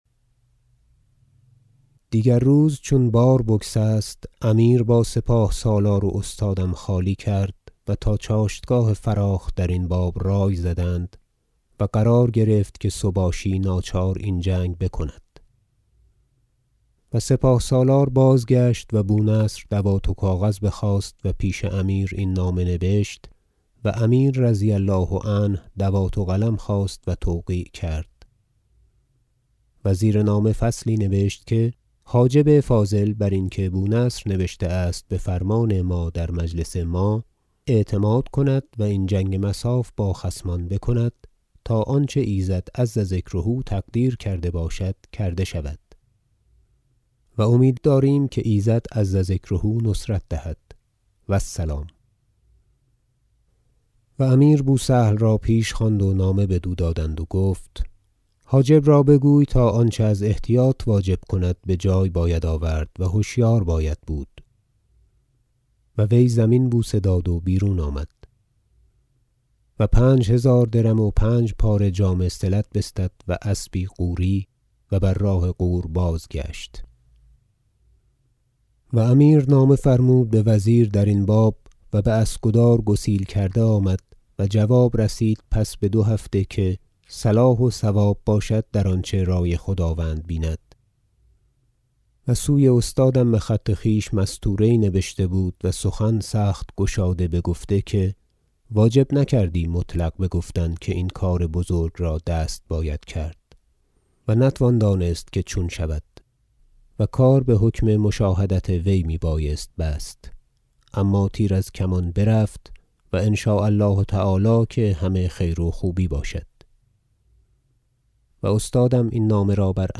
تاریخ بیهقی » مجلد نهم بخش ۱ - رفتن سباشی سوی سرخس به خوانش